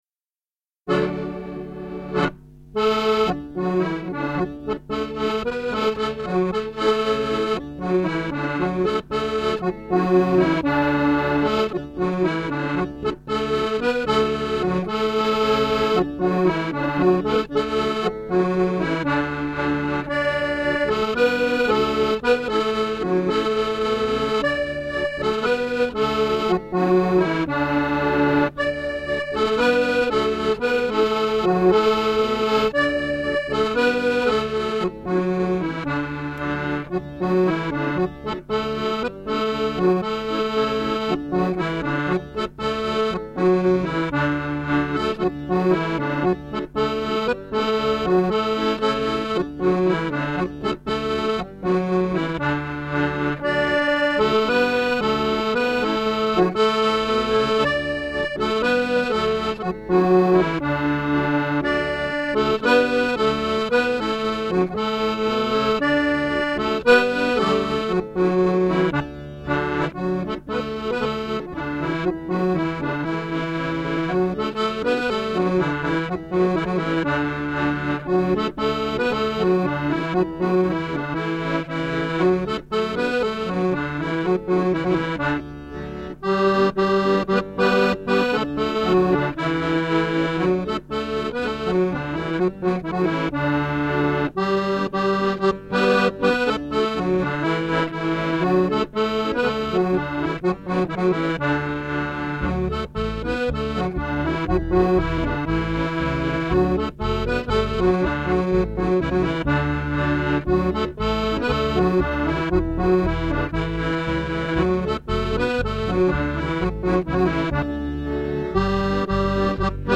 Music - 32 bar reels or jigs